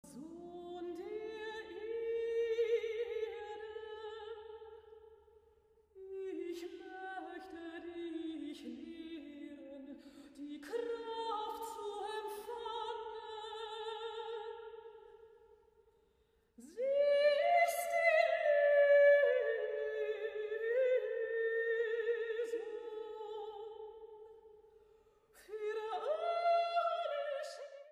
Neue Musik
Vokalmusik
Solostimme(n)